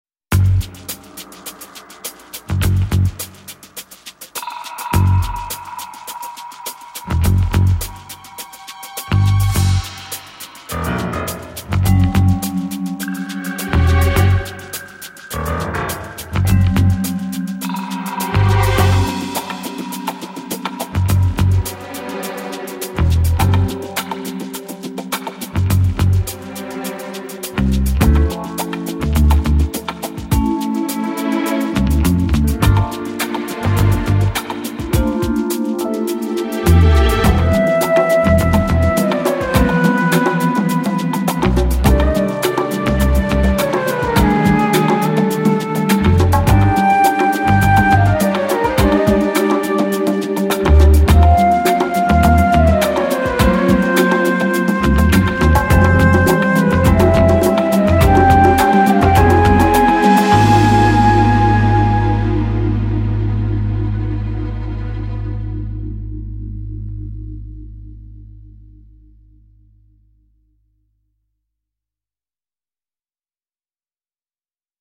Misteriose, oniriche, eccentriche, sinistre e tensive.